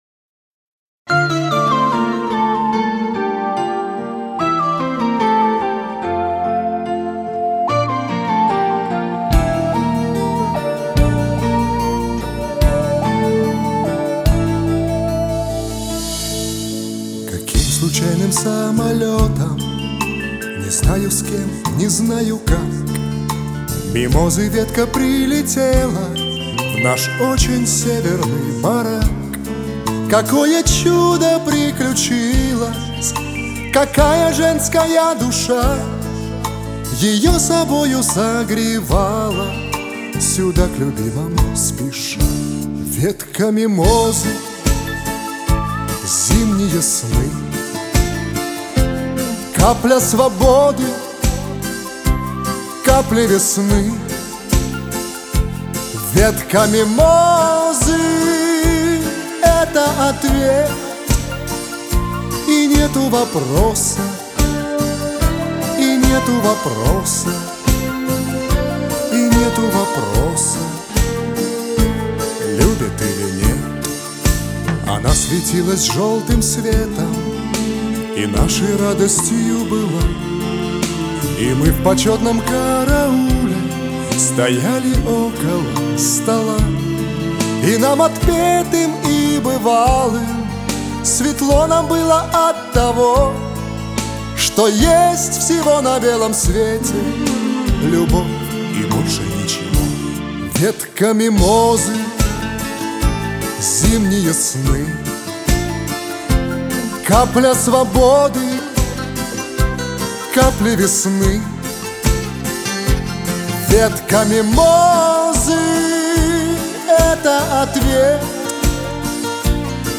您当前位置：网站首页 > 香颂（шансон）界
抒情的旋律，搭配着对含羞草的描述，来刻画受刑人的心灵世界。